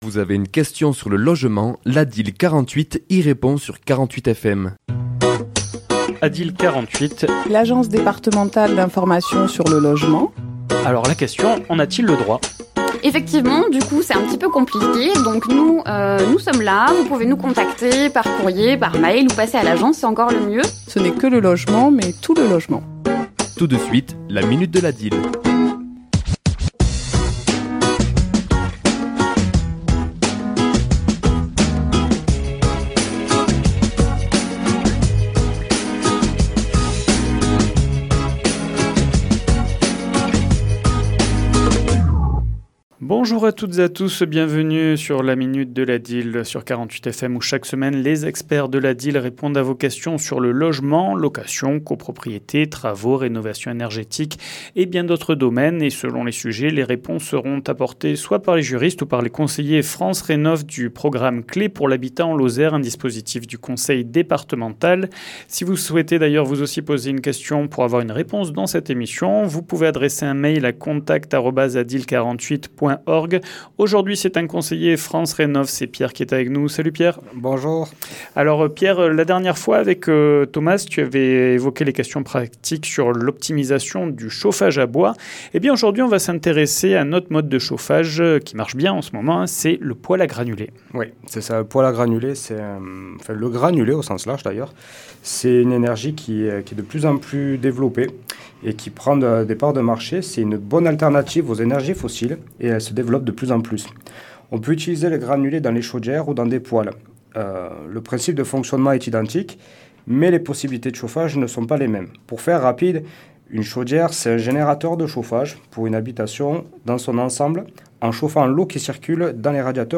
ChroniquesLa minute de l'ADIL